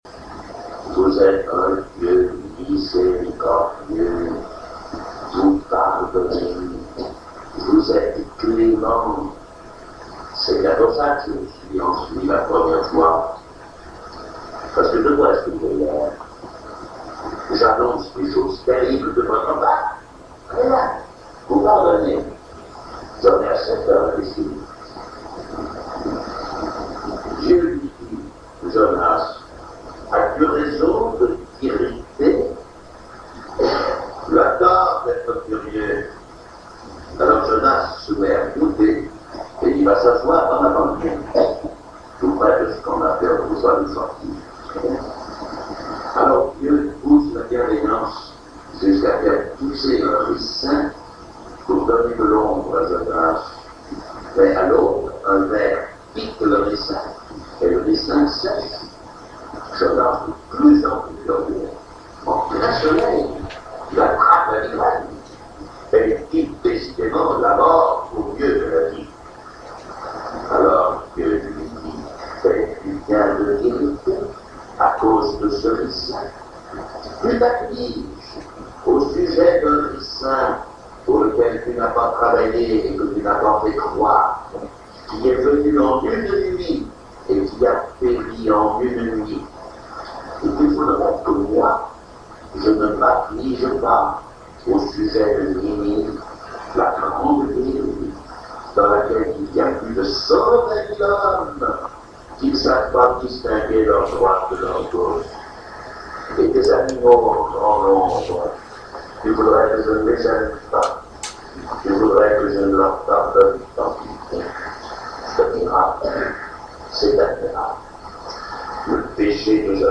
Le Père François Varillon raconte l’histoire de Jonas